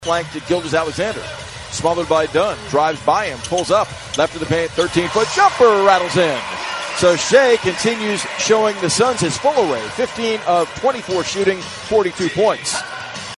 Thunder PBP - SGA 4 42.mp3